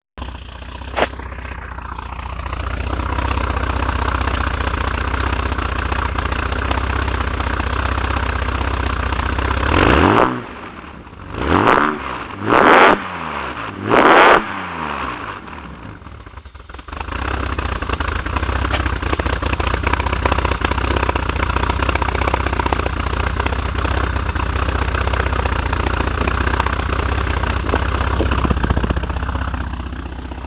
Dat klinkt zeker wel vet :D
vette sound :!: